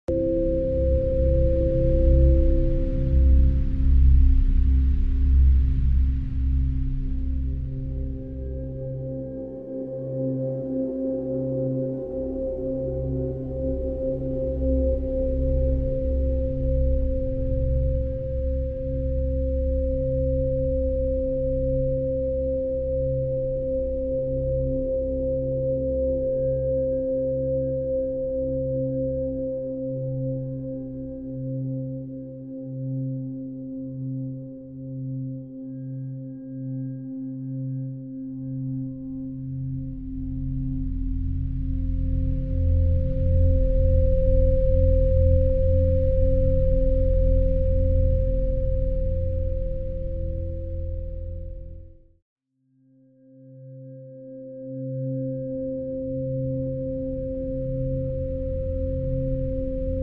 525HZ sound effects free download